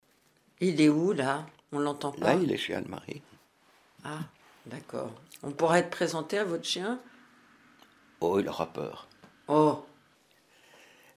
¤ 14 septembre 2011 : dans la catégorie "interview culturelle haut de gamme" sur France Culture, voici